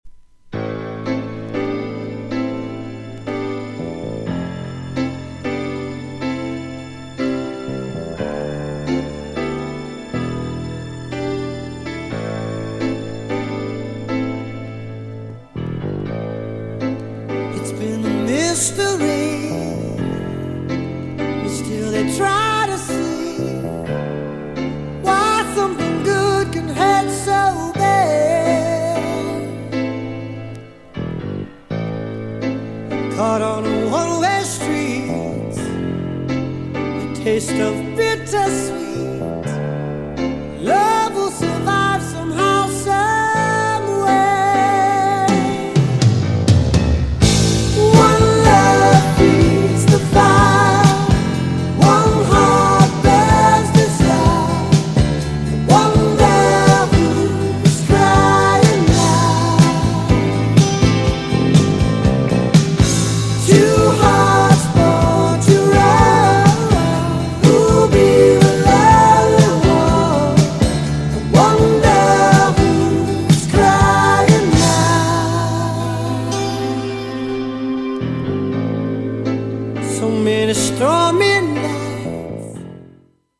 GENRE Dance Classic
BPM 101〜105BPM
# POP # スロー # ロック